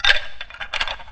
PixelPerfectionCE/assets/minecraft/sounds/mob/witherskeleton/say1.ogg at mc116